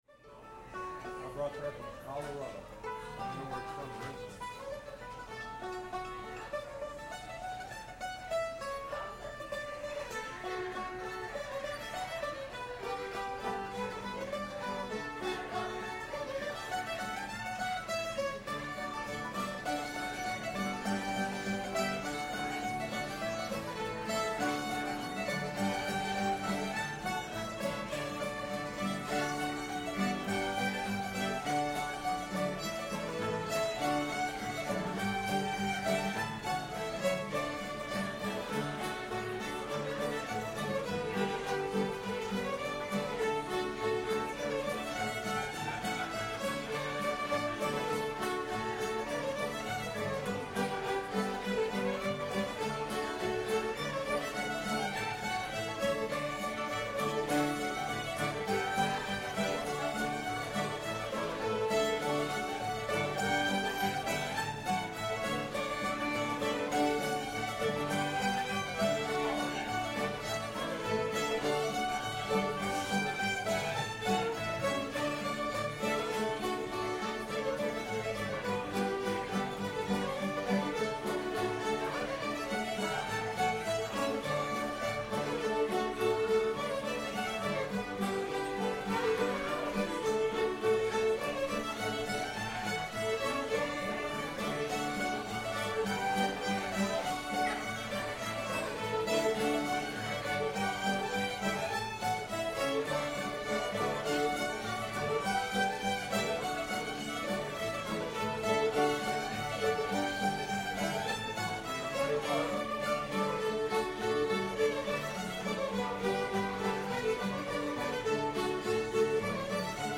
sam and elsie [D]